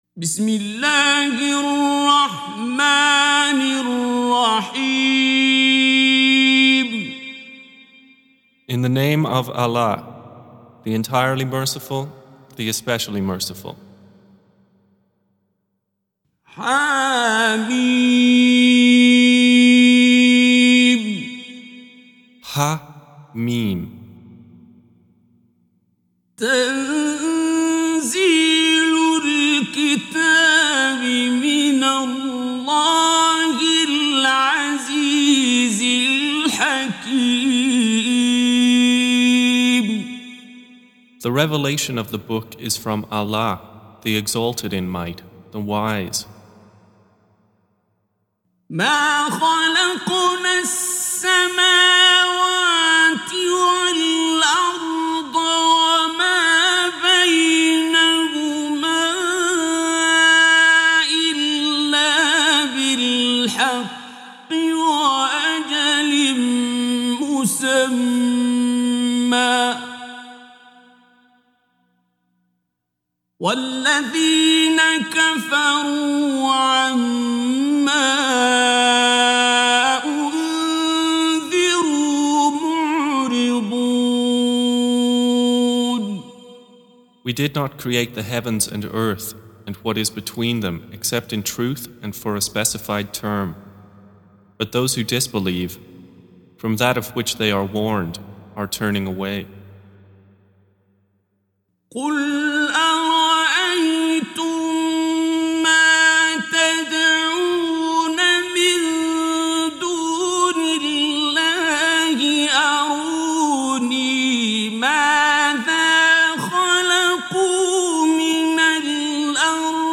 Surah Repeating تكرار السورة Download Surah حمّل السورة Reciting Mutarjamah Translation Audio for 46. Surah Al-Ahq�f سورة الأحقاف N.B *Surah Includes Al-Basmalah Reciters Sequents تتابع التلاوات Reciters Repeats تكرار التلاوات